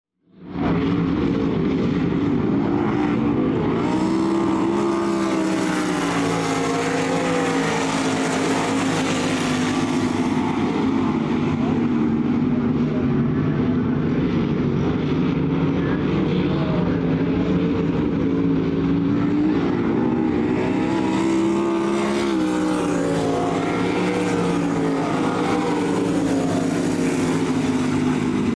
Half Mile Sprint Cars >>> Sound Effects Free Download